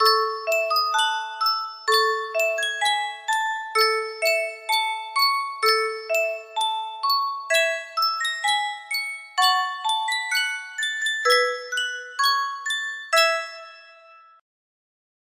Sankyo Music Box - On Wisconsin RJP music box melody
Full range 60